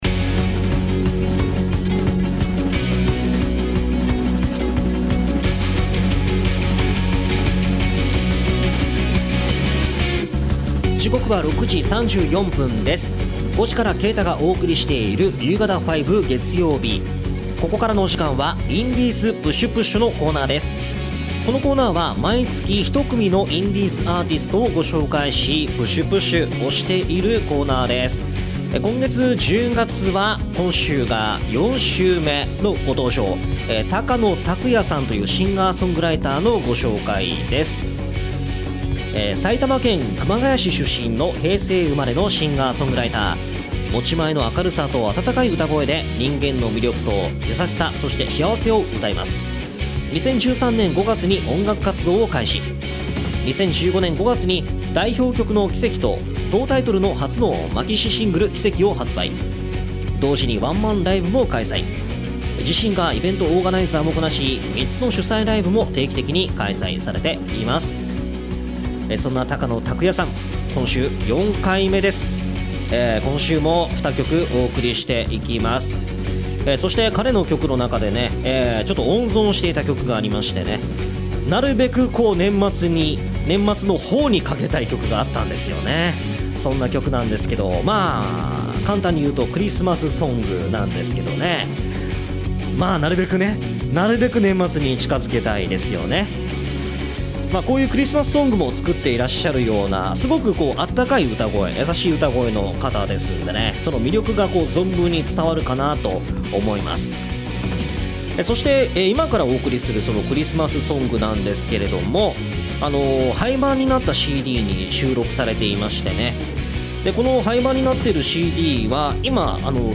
今回の放送同録音源はこちら↓